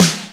HR16Snr2.wav